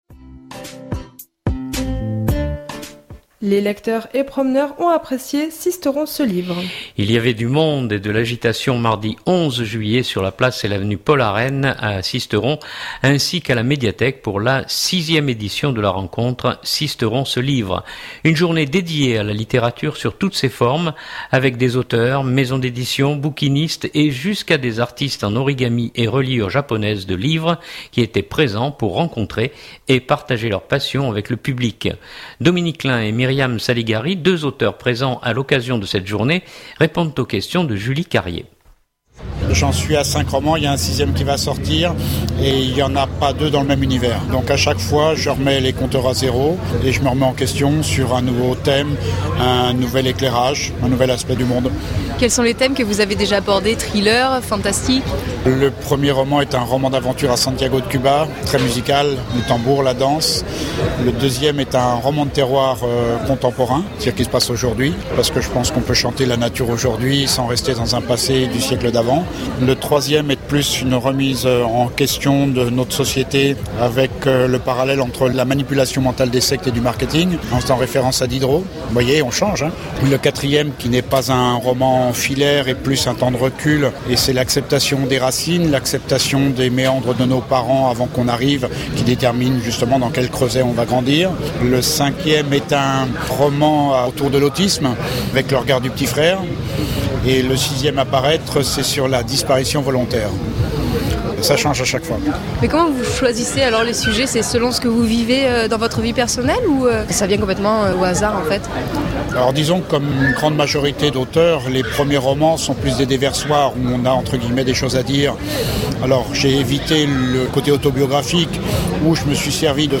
deux auteurs présents à l’occasion de cette journée répondent aux questions